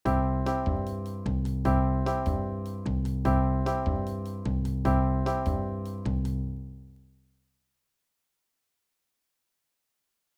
ピアノをつけたもの
アフロキューバン2.wav